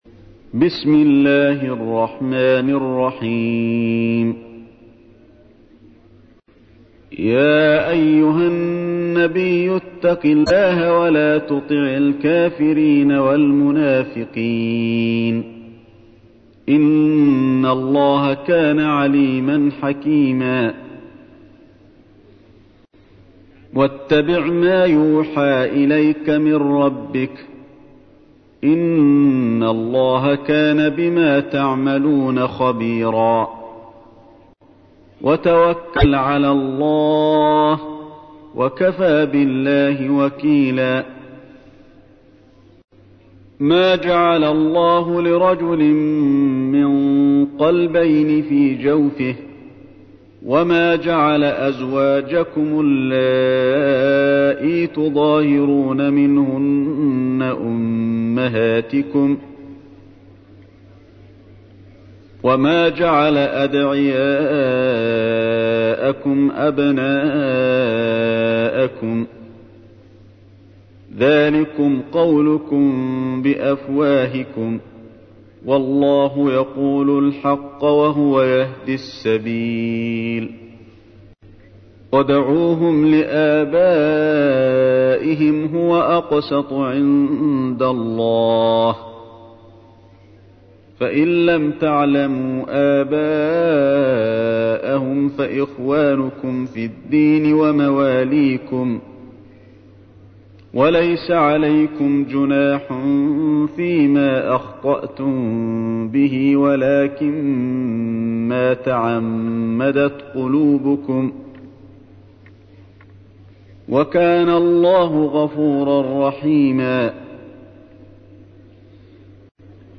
تحميل : 33. سورة الأحزاب / القارئ علي الحذيفي / القرآن الكريم / موقع يا حسين